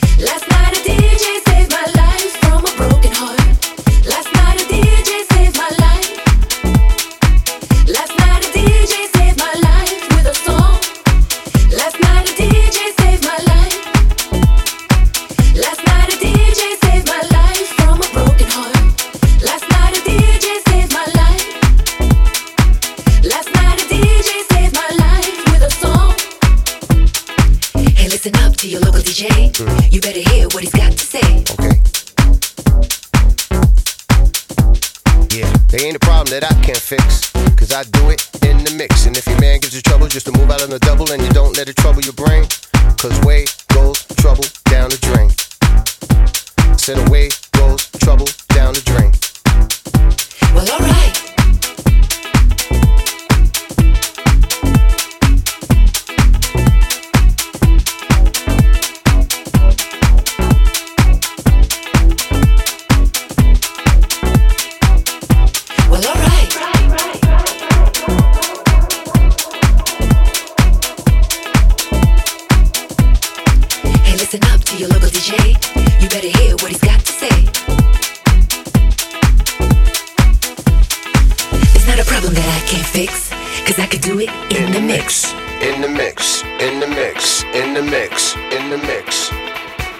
peak house groover